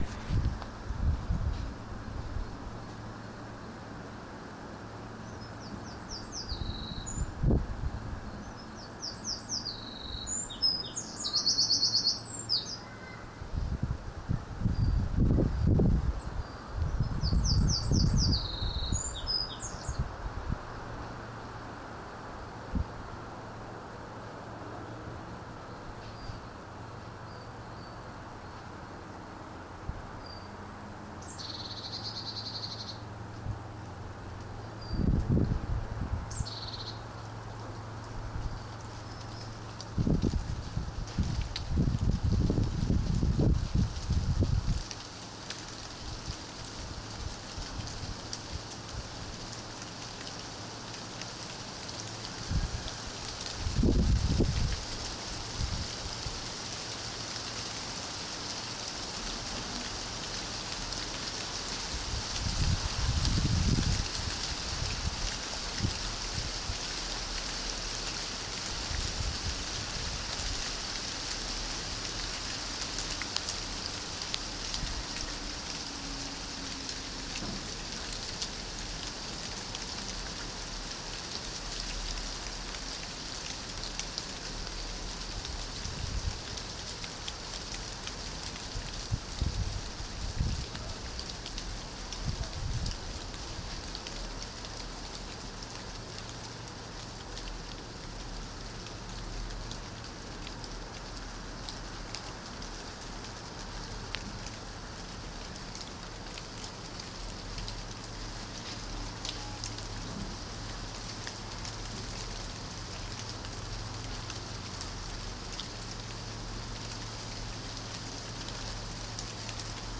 a small shower of rain